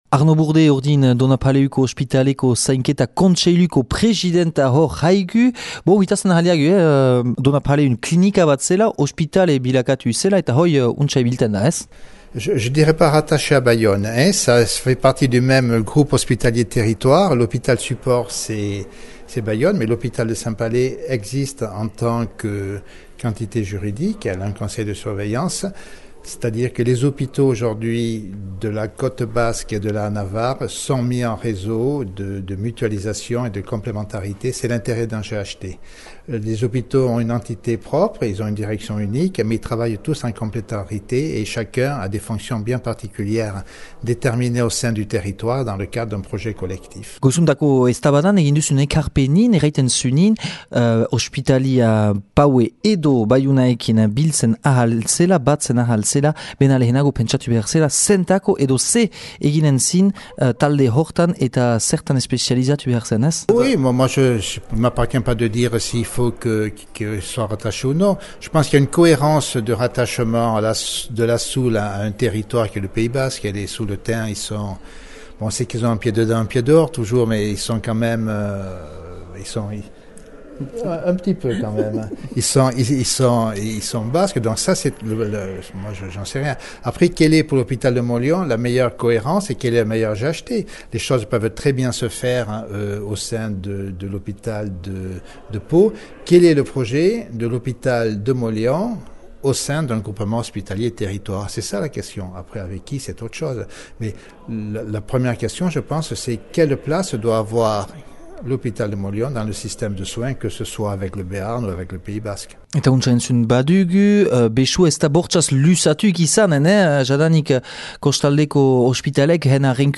Üngürgiro hortan dü antolatü EH Bai alderdiak Ospitalearen geroaz mahain üngürü bat neskenegünean.